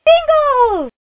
One of Princess Peach's voice clips in Mario Kart: Super Circuit